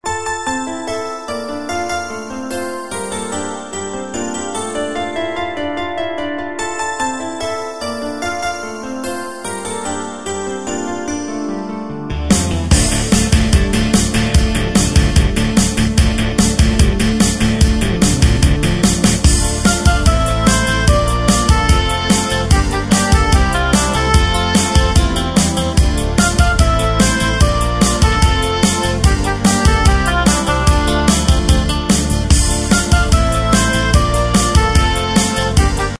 Tempo: 147 BPM.
MP3 s ML DEMO 30s (0.5 MB)zdarma